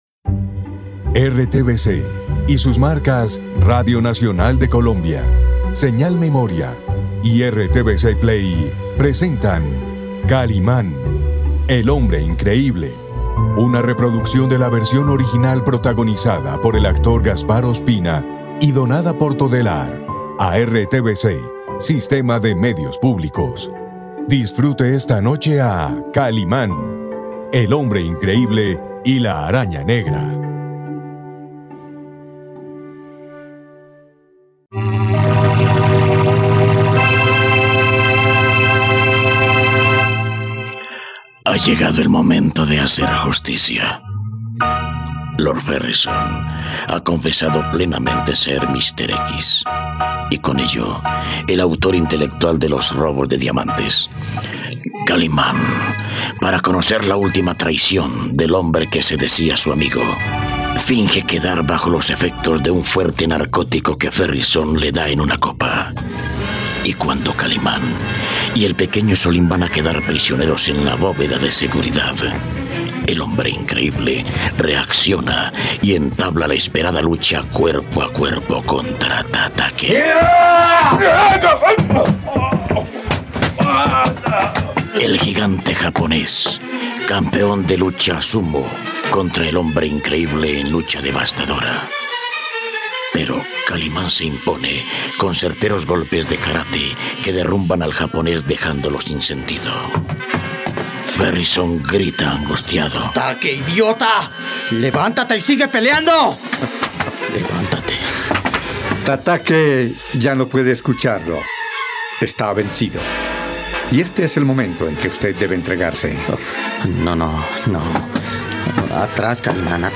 radionovela